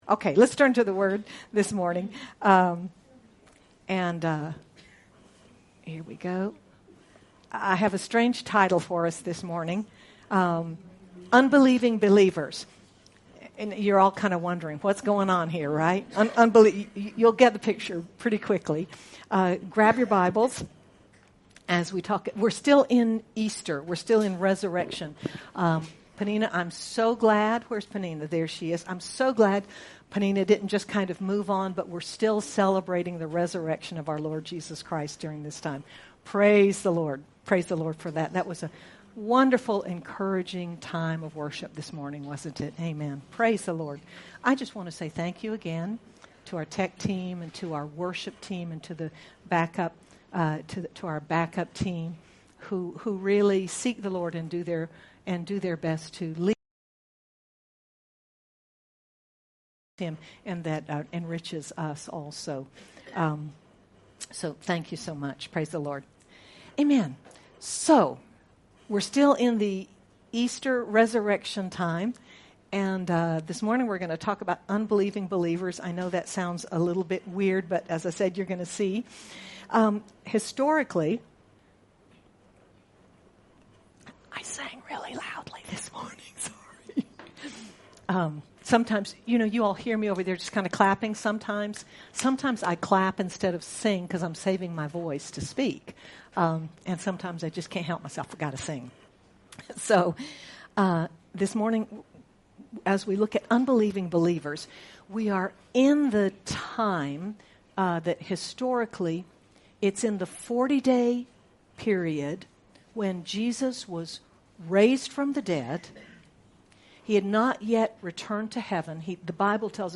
In this message looking at doubting Thomas and the other followers who cannot believe Jesus is alive, we are challenged to consider our own lives and whether or not we really believe Jesus and His Words. Sermon by